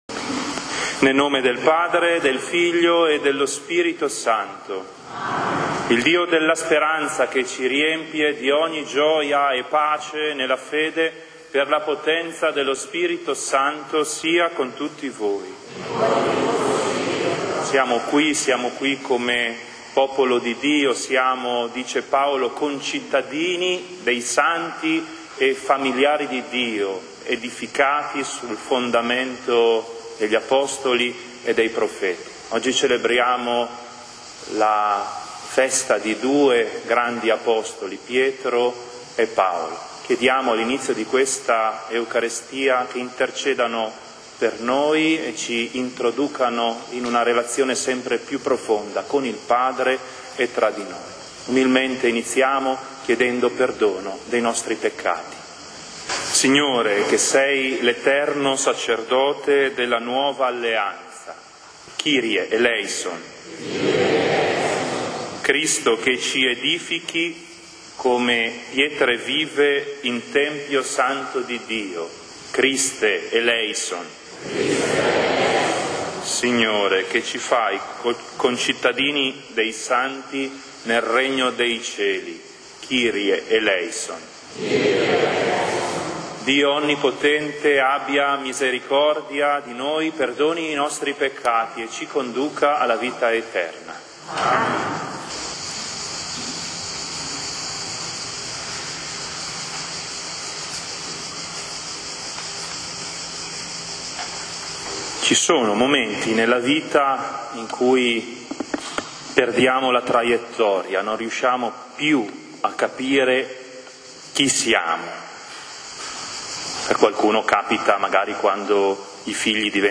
Parrocchia San Giorgio Martire | Omelie della domenica: ARCHIVIO AUDIO | Omelie della domenica Anno Liturgico C 2024-2025